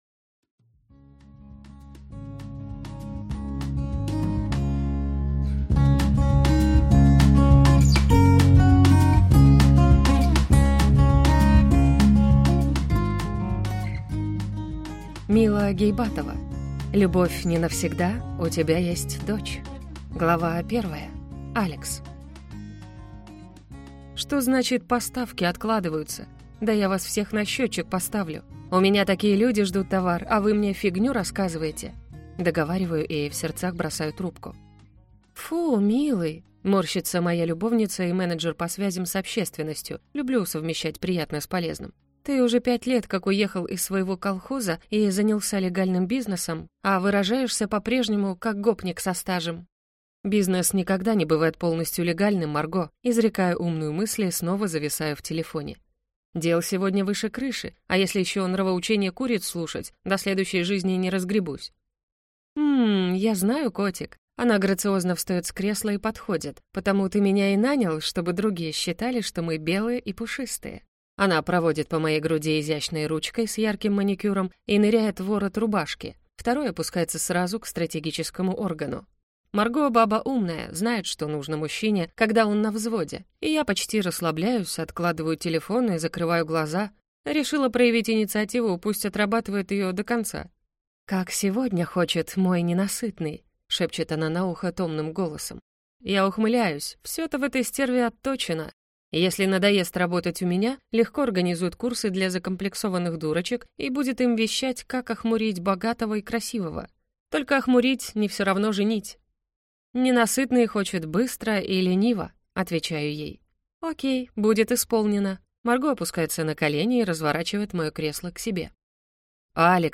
Аудиокнига Любовь (не) навсегда. У тебя есть дочь | Библиотека аудиокниг
Прослушать и бесплатно скачать фрагмент аудиокниги